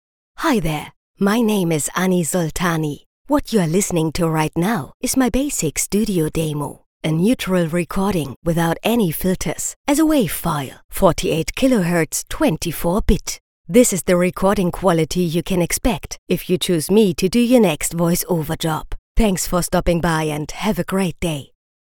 Neumann TLM 103 condenser microphone, pop shield
Speaker booth
Download | Home-Studio Demo | wave, 48 kHz, 24 bit